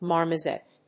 音節mar・mo・set 発音記号・読み方
/mάɚməsèt(米国英語), mάːməsèt(英国英語)/